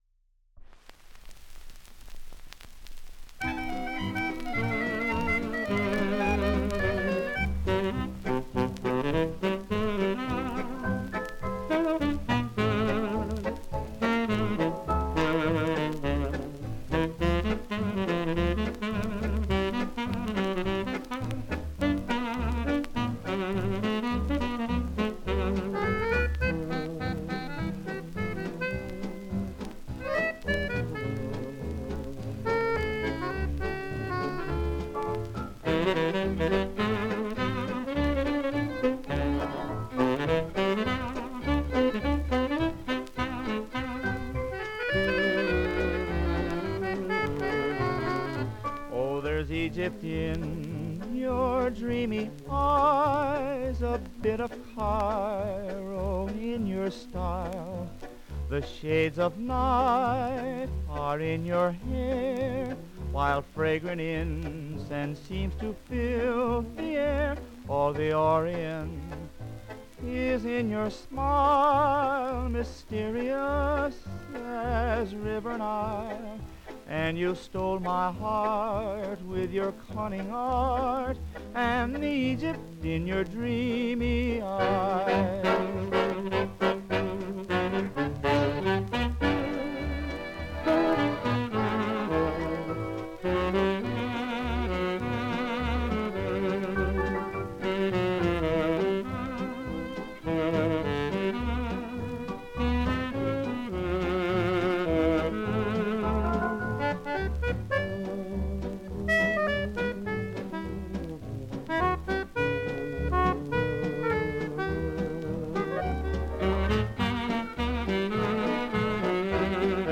Genre: Medley.